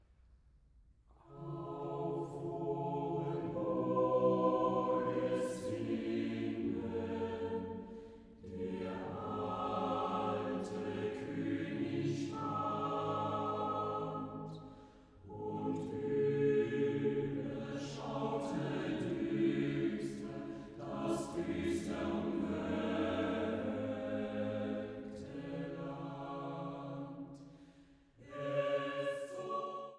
Komposition für gemischten Chor